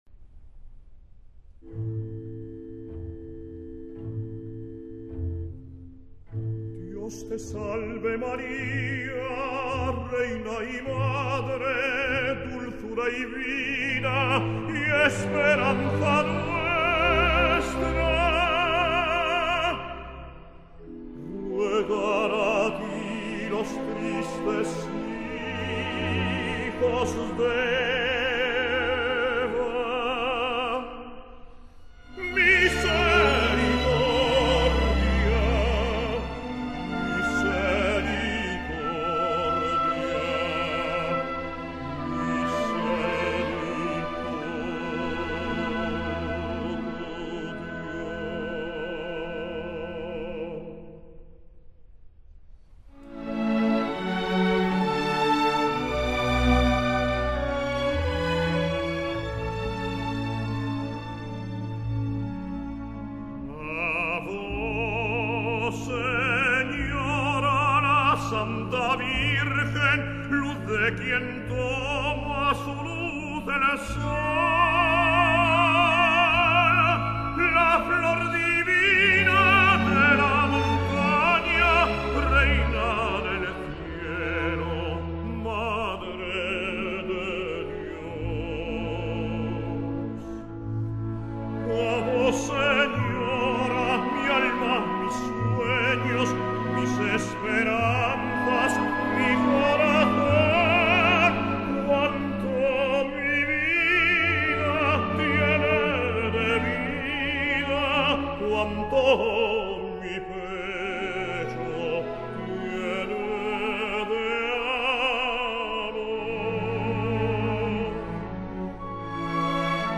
Género: Blues.